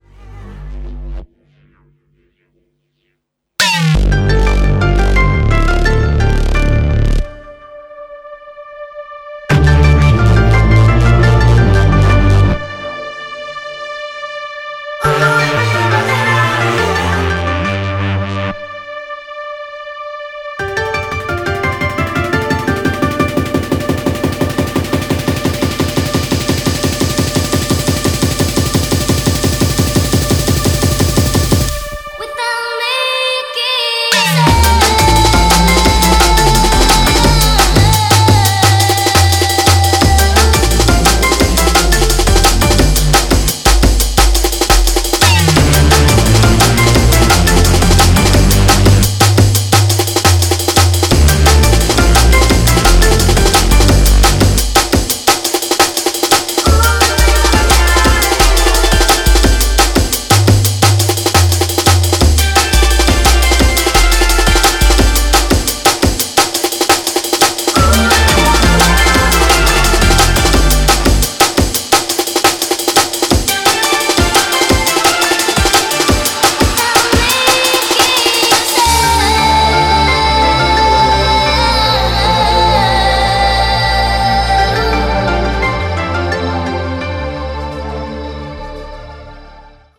Electro Electronix Techno Ambient